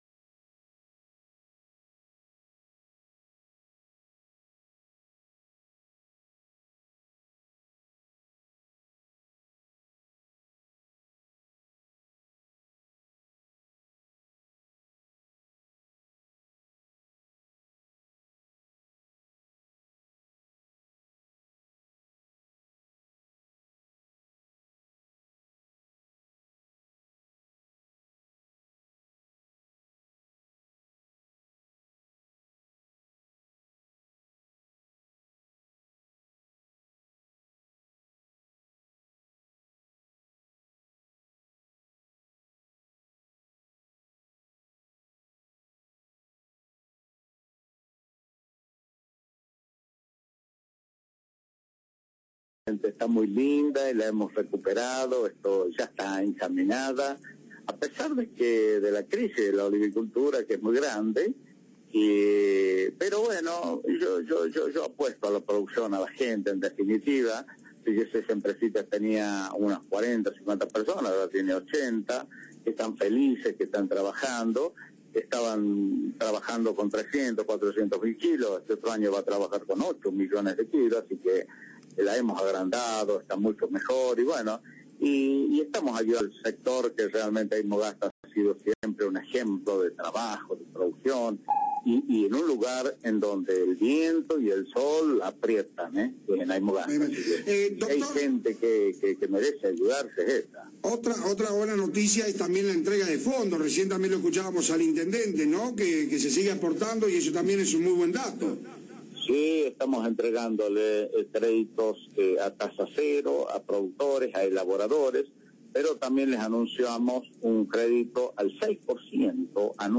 Luis Beder Herrera, gobernador, por Radio Fénix